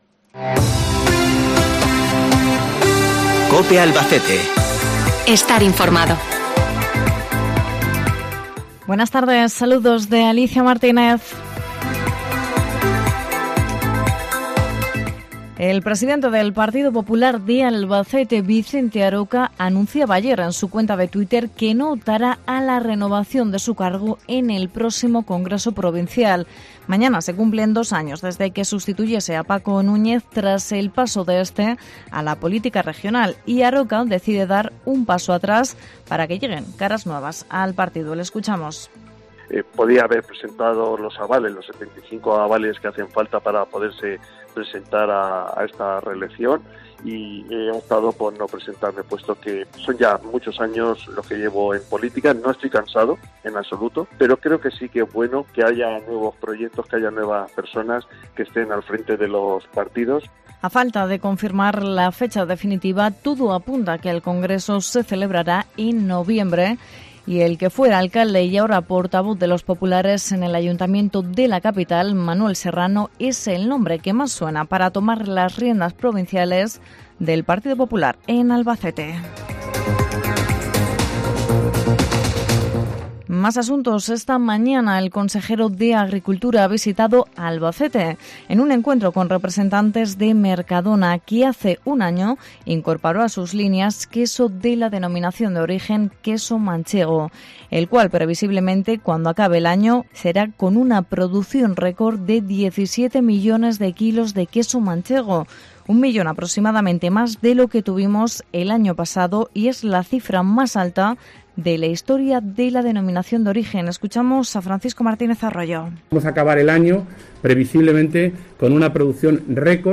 INFORMATIVO LOCAL 21 DE OCTUBRE